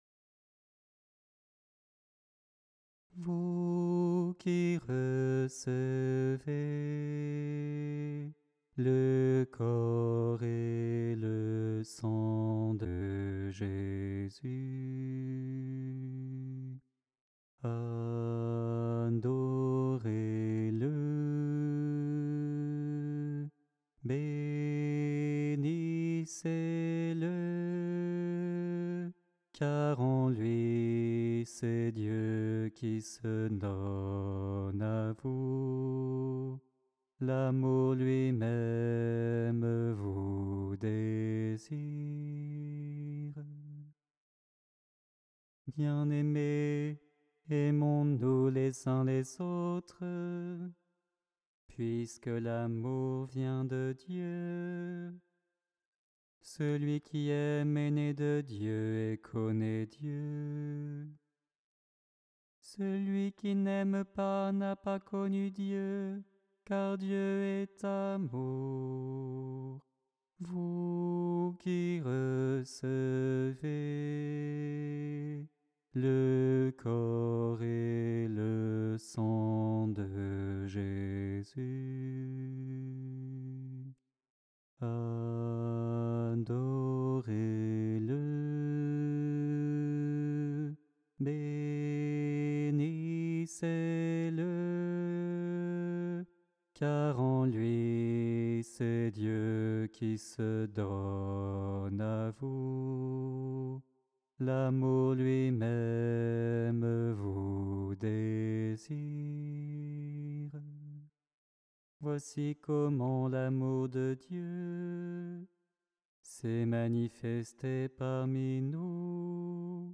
Voix chantée (MP3)COUPLET/REFRAIN
BASSE